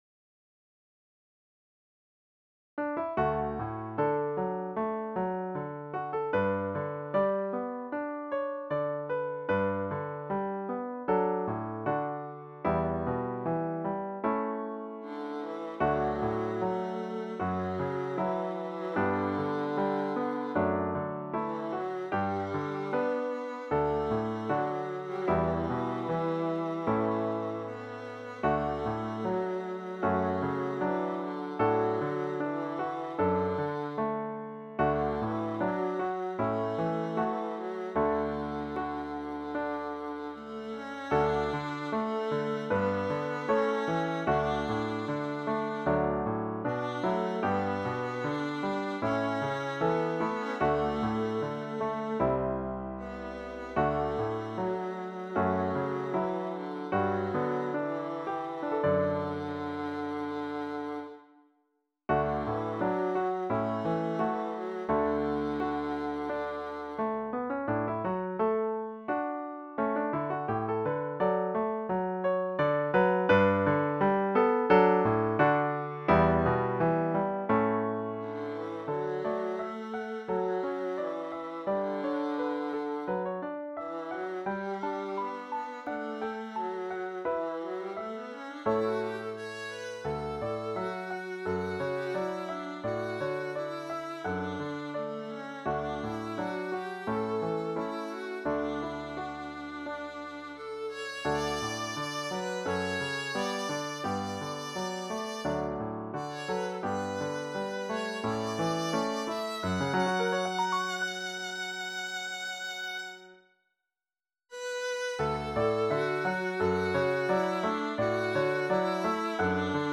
Intermediate Instrumental Solo with Piano Accompaniment.
Christian, Gospel, Sacred, Folk.
A Hymn arrangement
put to a flowing folk setting.